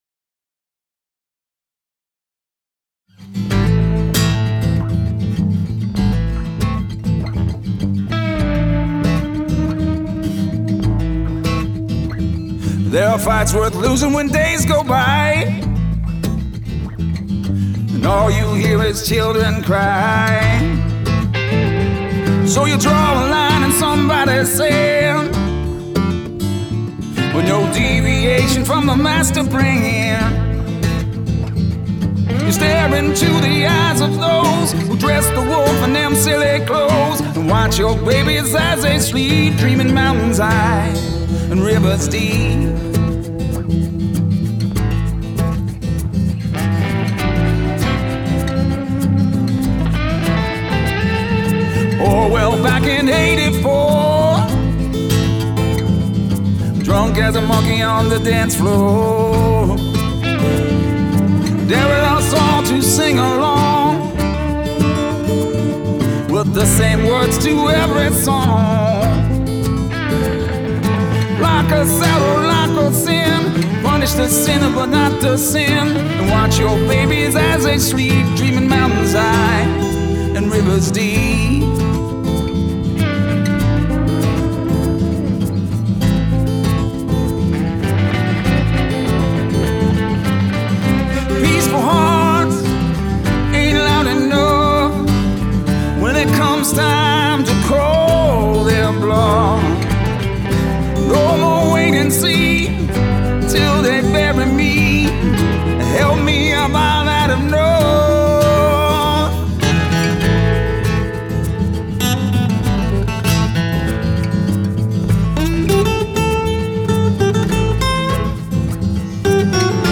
Vocals, guitars
recorded in quarantine from our separate bunkers